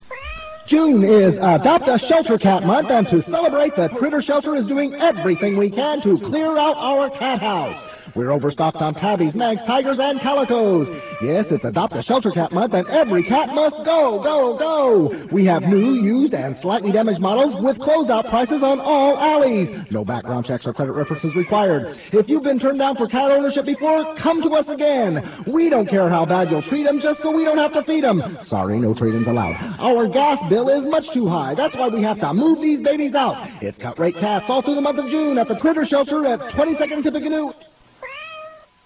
I am all voices.
The sound quality is very good considering how much it scrunches the files.
Most of those examples were captured on normal bias analog tape.